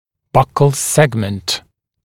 [ˈbʌkl ‘segmənt][ˈбакл ‘сэгмэнт]боковой сегмент